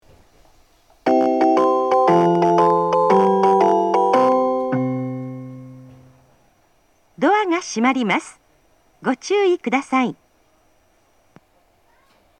発車メロディー
こちらも一度扱えばフルコーラス鳴ります。
こちらはなぜか元から音割れしています。